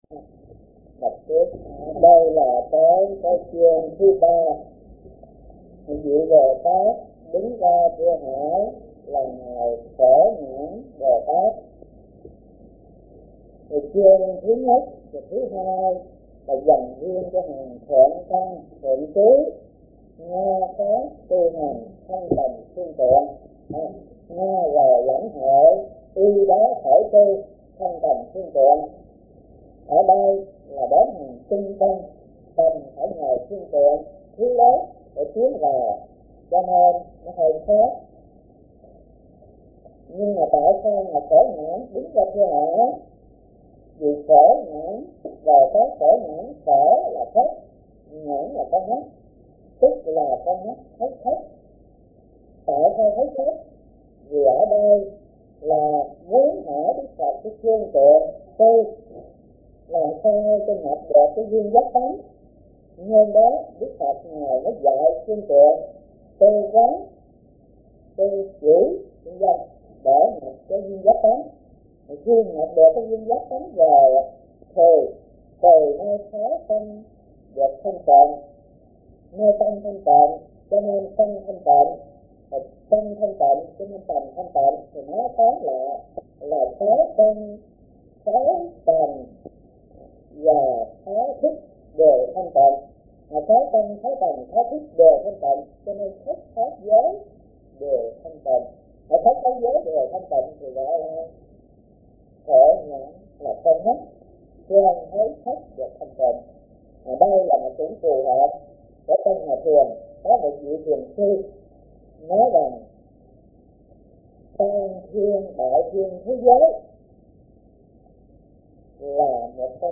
Kinh Giảng Kinh Viên Giác